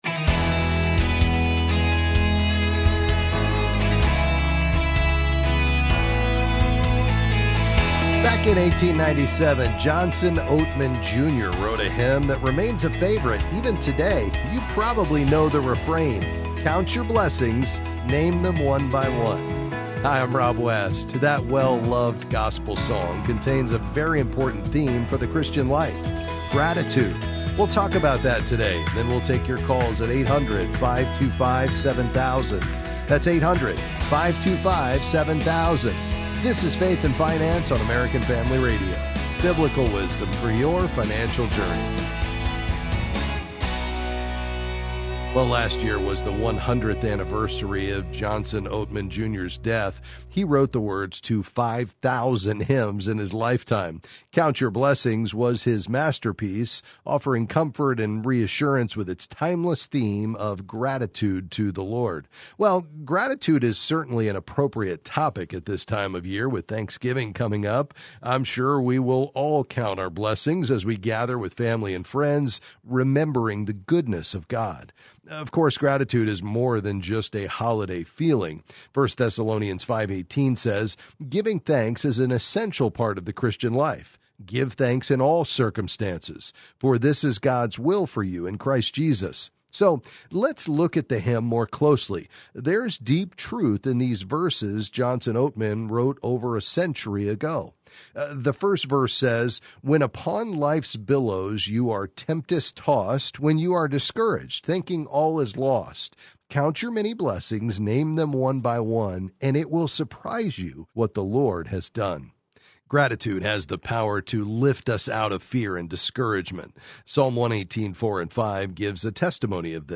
Then he answers your calls and financial questions.